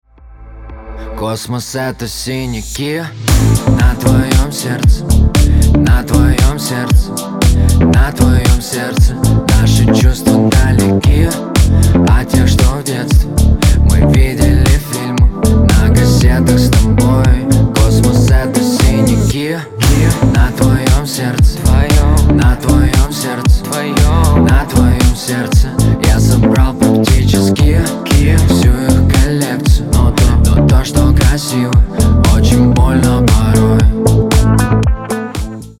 мужской голос
спокойные